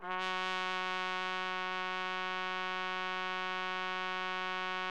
TRUMPET    1.wav